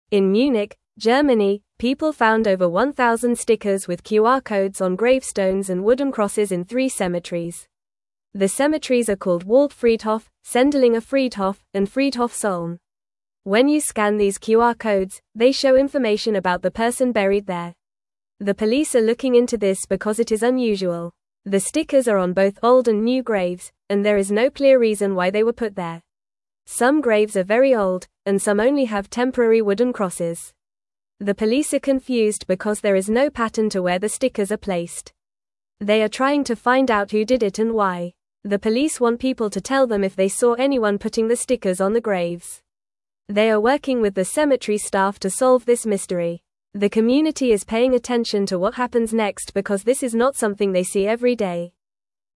Fast
English-Newsroom-Lower-Intermediate-FAST-Reading-Stickers-on-Graves-Tell-Stories-of-the-Past.mp3